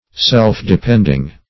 Self-depending \Self`-de*pend"ing\, a. Depending on one's self.